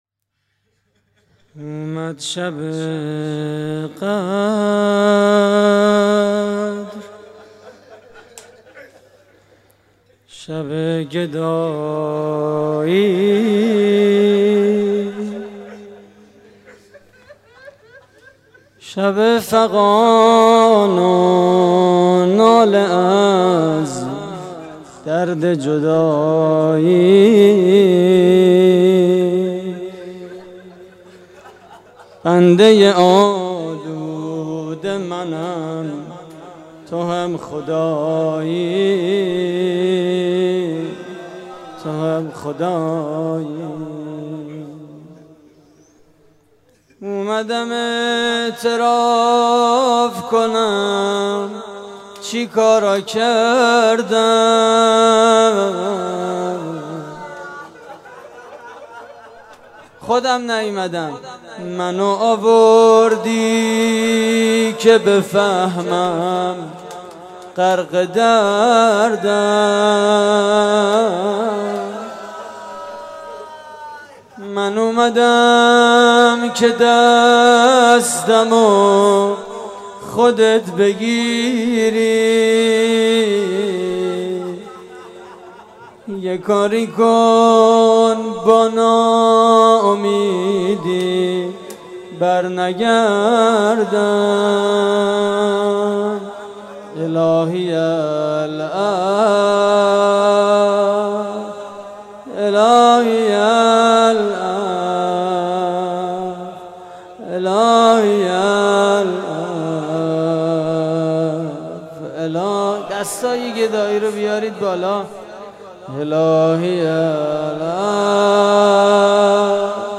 صوت مراسم:
زمزمه: اومد شب قدر؛ پخش آنلاین |
مناجات